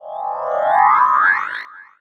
magic_device_transform_04.wav